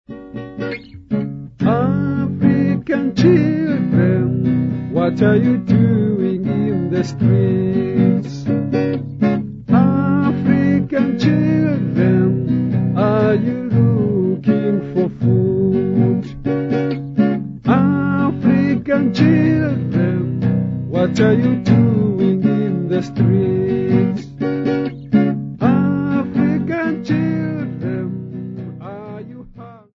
Folk music -- South Africa
Guitar
Sub-Saharan African music
Africa South Africa Grahamstown f-sa
field recordings
Topical song with guitar accompaniment